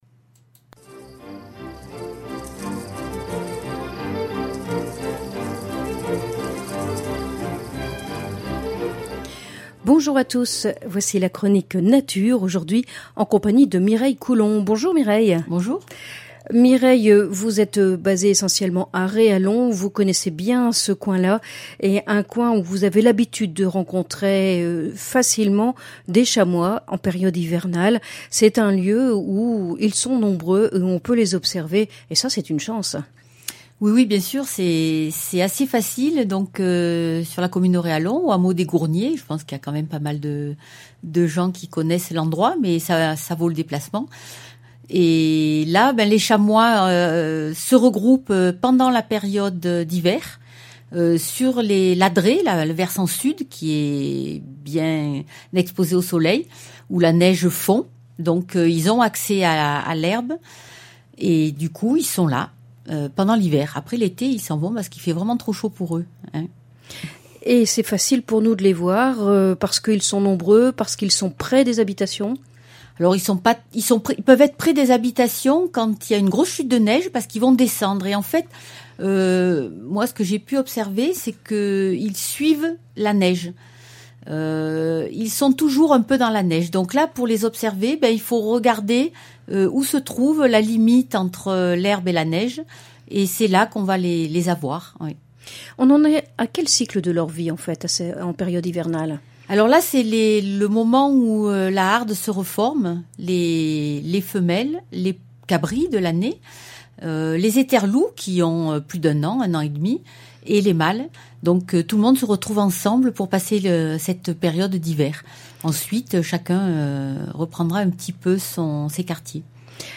• Chronique nature